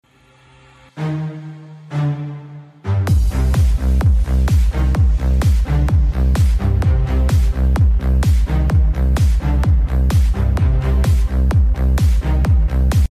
Three-way subwoofer